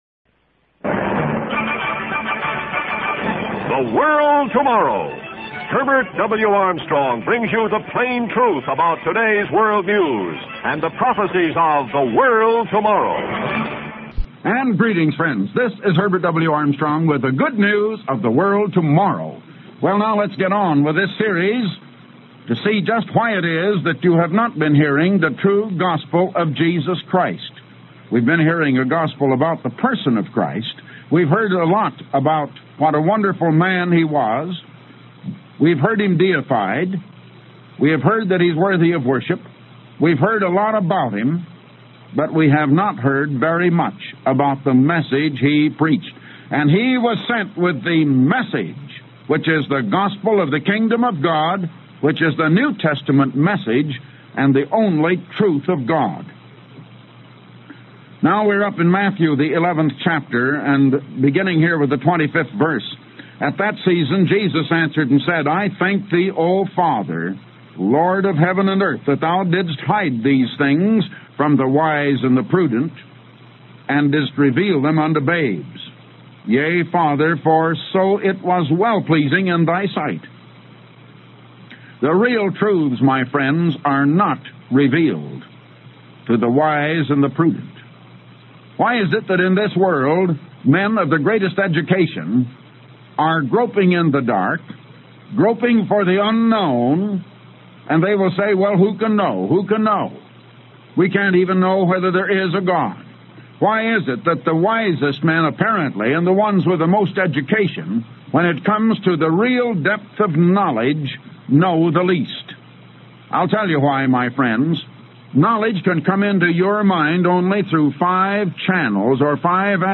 Type: Radio Broadcast